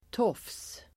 Uttal: [tåf:s]